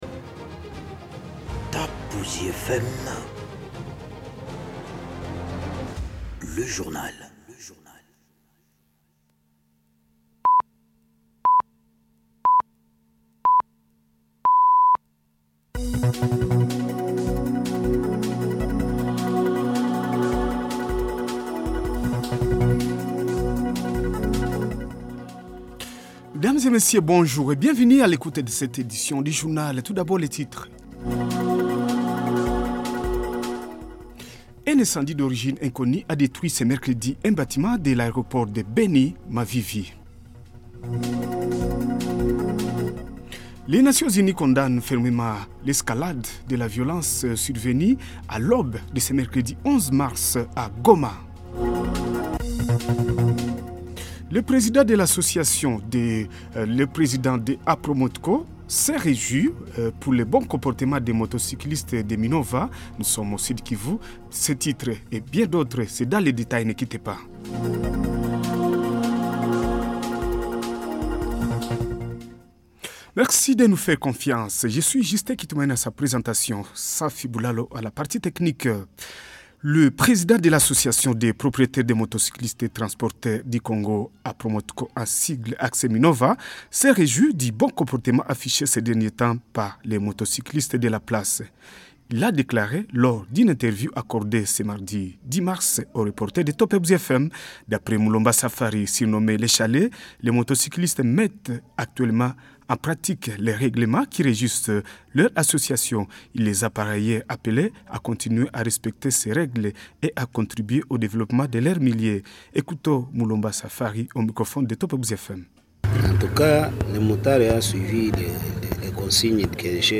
Journal soir ce mercredi 11 mars 2026